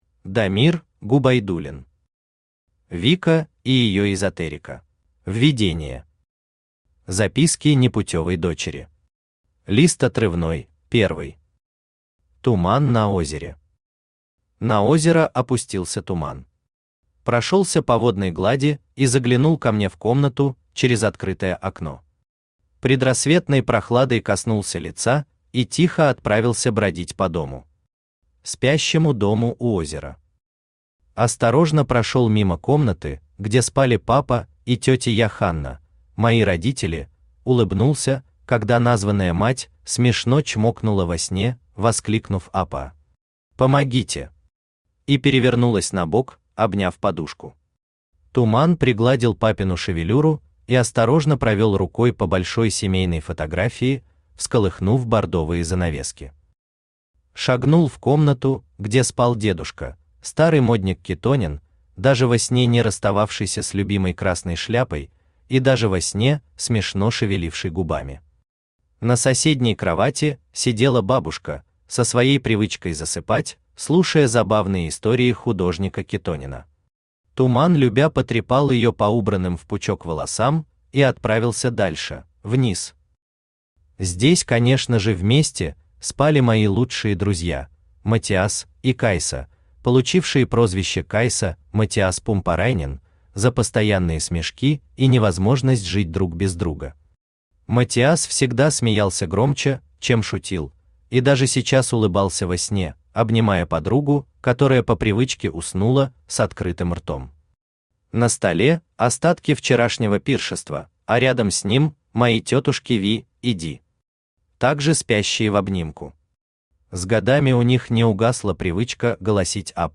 Аудиокнига Вика и ее эзотерика | Библиотека аудиокниг
Aудиокнига Вика и ее эзотерика Автор Дамир Губайдуллин Читает аудиокнигу Авточтец ЛитРес.